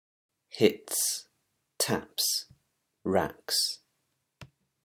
If it ends in a voiceless sound then the s / es is pronounced /s/